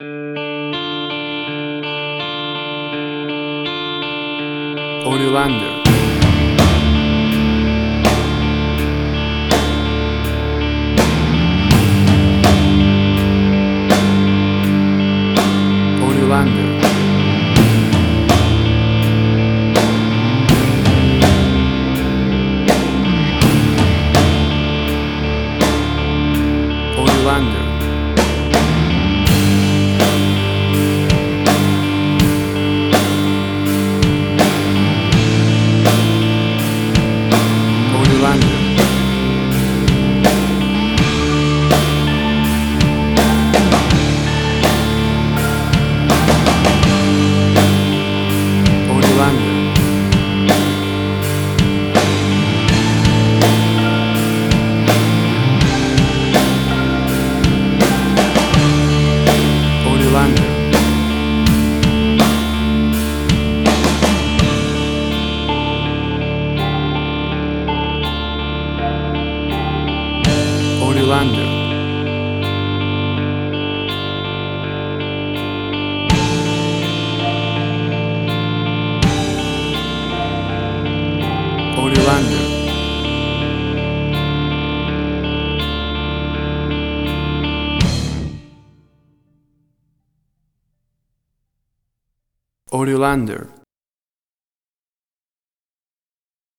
Hard Rock 101.jpeg
Heavy Metal
Tempo (BPM): 82